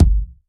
SBV_V12_Kick_003.wav